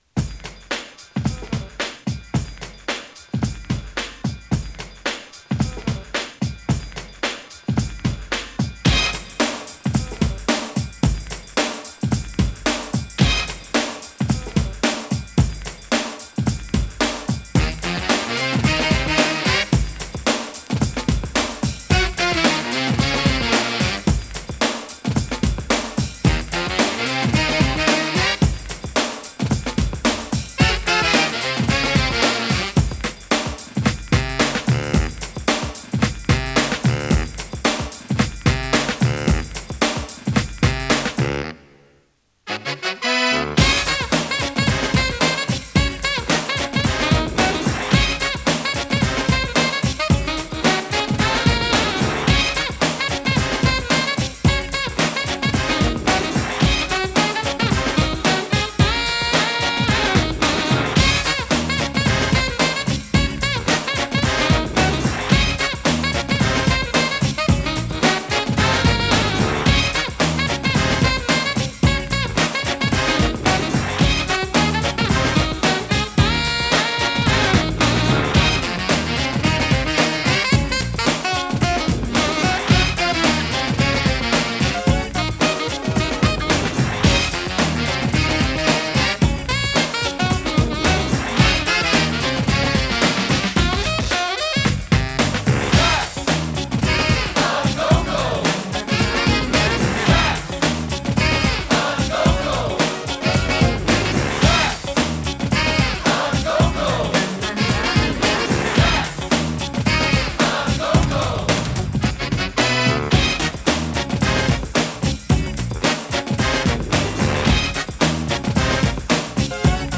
重 弾力 ファンキー パーティー HIP HOP バップ ジャズ
重く弾力のあるトラックにブロウするSAXがカッコいいパーティー・チューン！